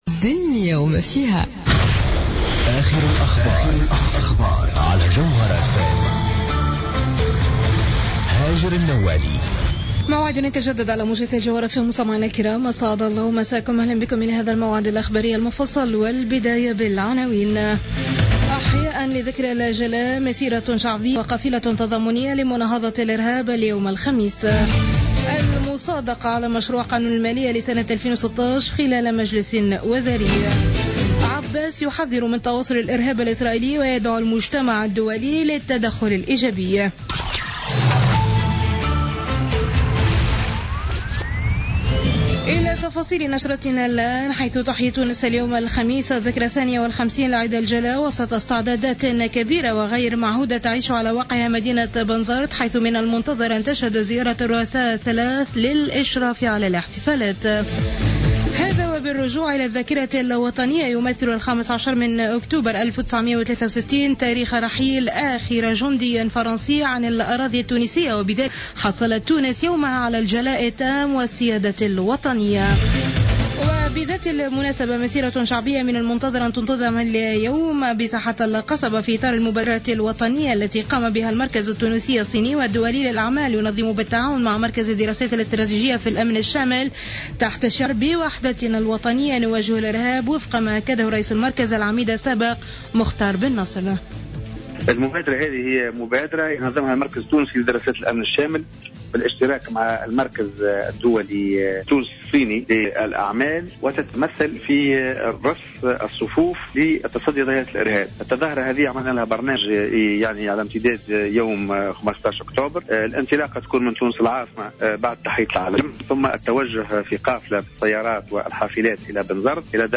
نشرة اخبار منتصف الليل ليوم الخميس 15 أكتوبر 2015